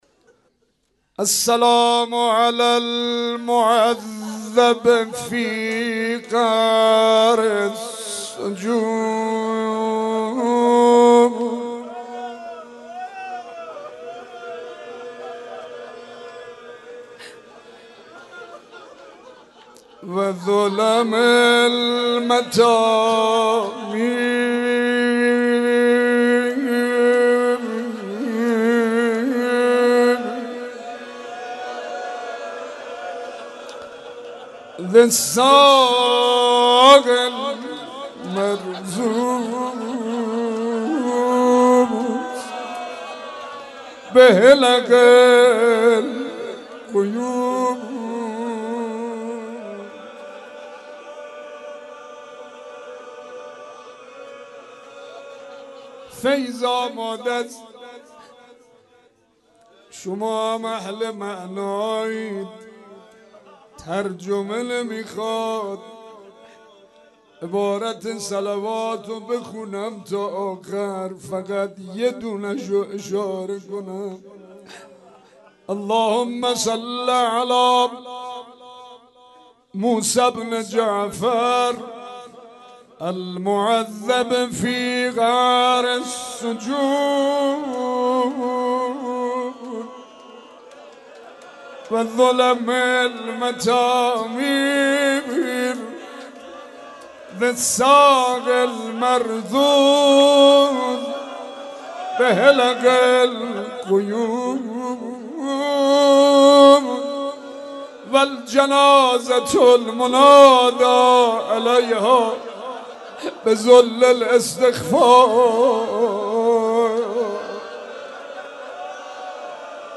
مرثیه امام کاظم